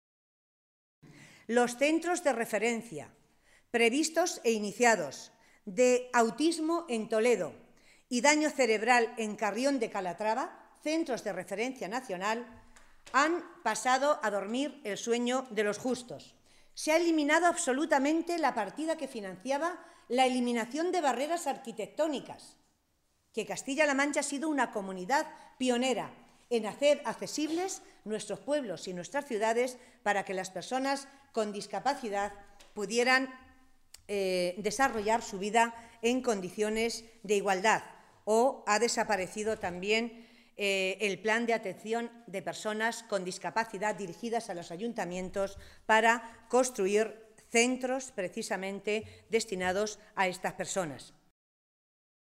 Matilde Valentín, portavoz de Asuntos Sociales del Grupo Socialista
Cortes de audio de la rueda de prensa